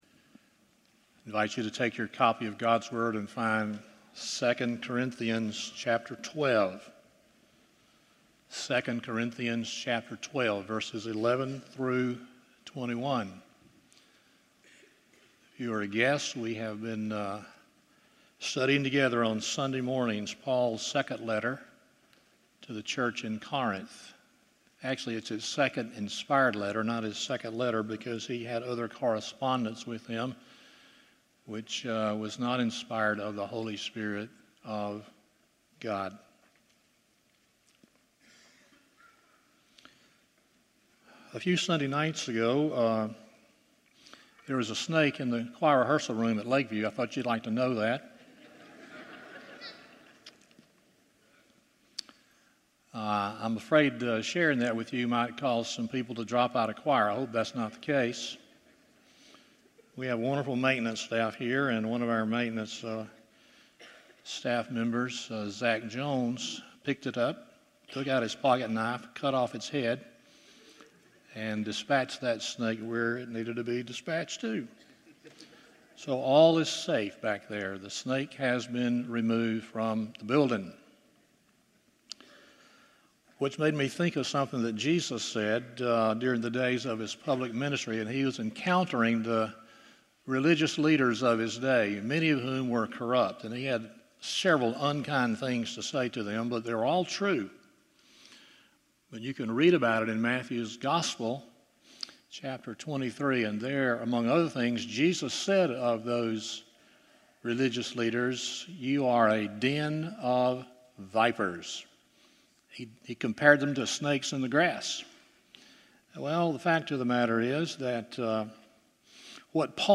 2 Corinthians 12:11-21 Service Type: Sunday Morning 1.